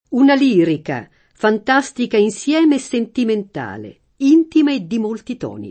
tono [t0no] (antiq. tuono [tU0no]) s. m. («tensione; modulazione; gradazione») — es. con acc. scr.: una lirica, fantastica insieme e sentimentale, intima e di molti tòni [
una l&rika, fant#Stika inSL$me e SSentiment#le, &ntima e ddi m1lti t0ni] (Carducci) — oggi non più in uso il dittongo -uo-, né in tono, né nei comp. intonare e stonare